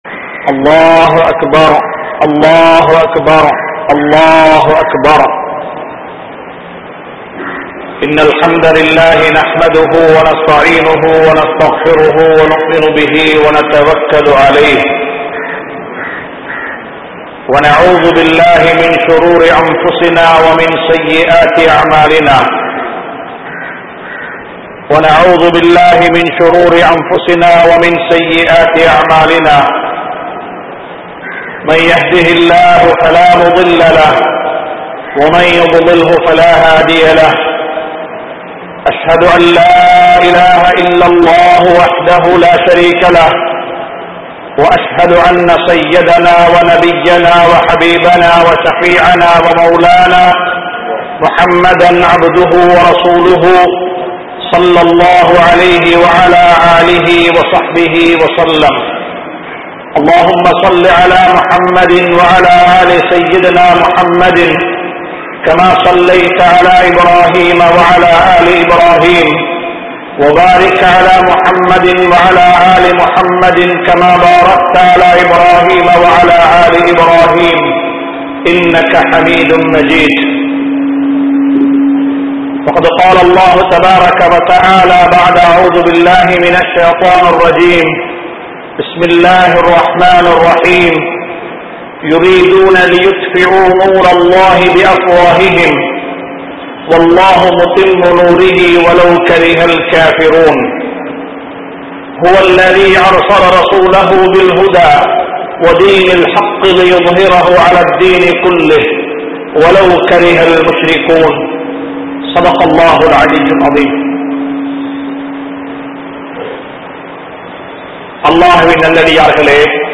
Petroarhalin Nilal Pillaihal (பெற்றோர்களின் நிழல் பிள்ளைகள்) | Audio Bayans | All Ceylon Muslim Youth Community | Addalaichenai